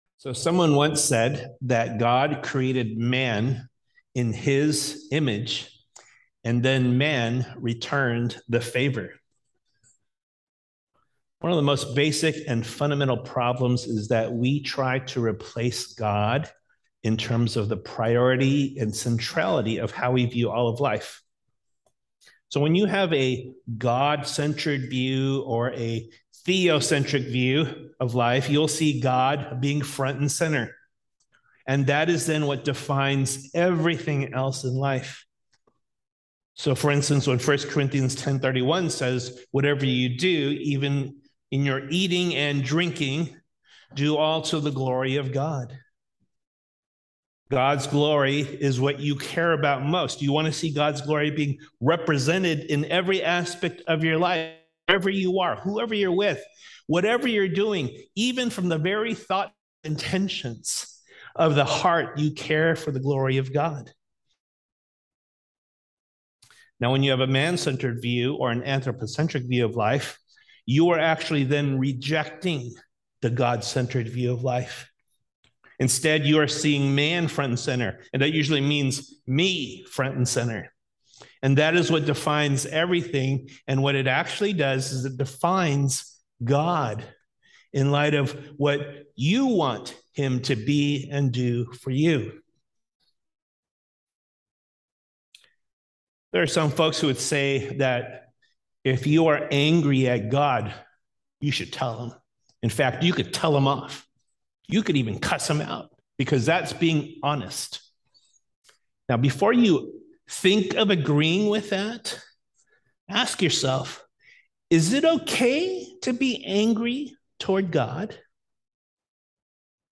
Anger | Sermon Series | Lighthouse Bible Church Los Angeles | Making Disciples of Christ.
Sunday Service)Bible Text